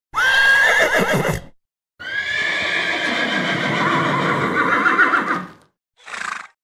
Horse Neigh Sfx Téléchargement d'Effet Sonore
Horse Neigh Sfx Bouton sonore